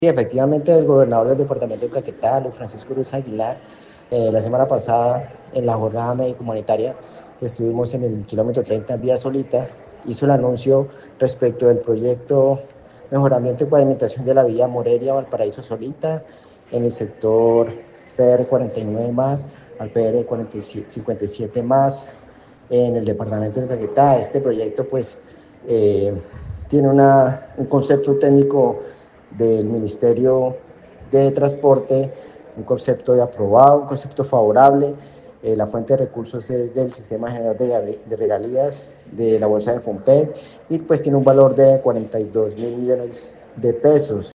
De acuerdo con el secretario de planeación departamental, Diego Pinto, el proyecto cuenta con conceptos favorables y se financiará con recursos del sistema general de regalías.